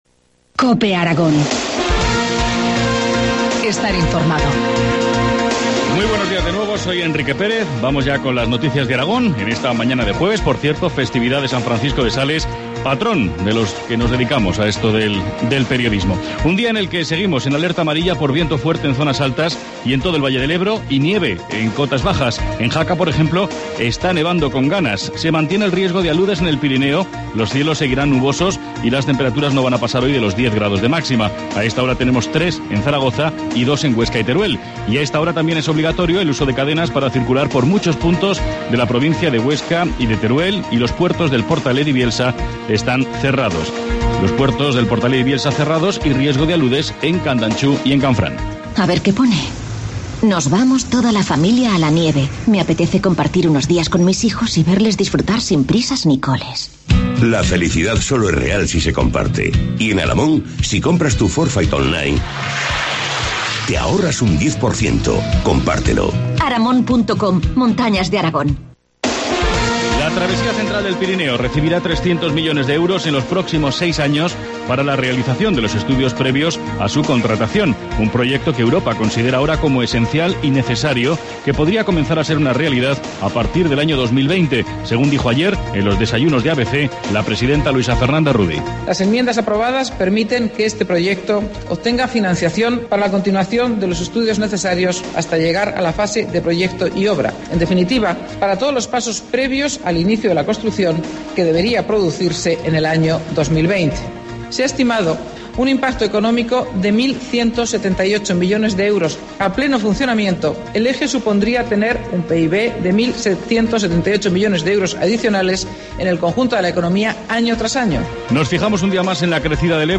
Informativo matinal, jueves 24 de enero, 8.25 horas